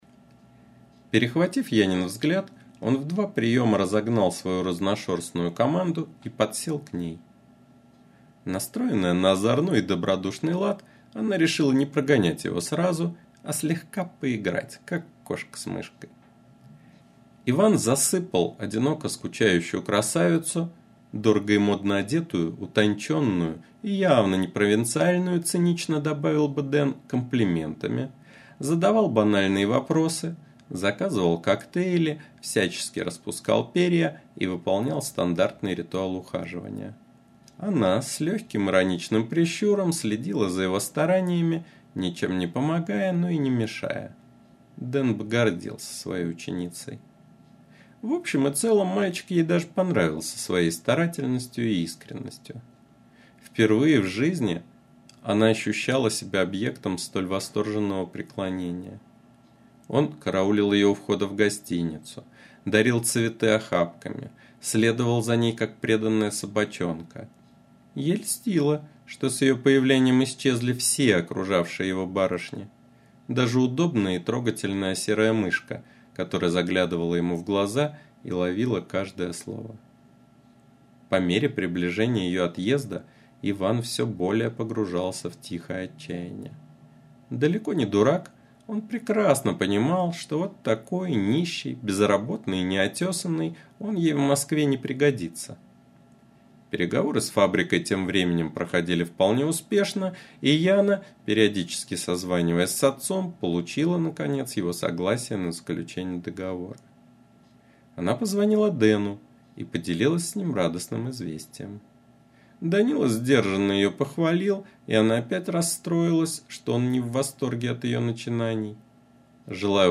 Аудиокнига "Nе наша планета", .mp3